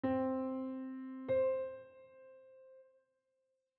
C-Octave-Interval-S1.wav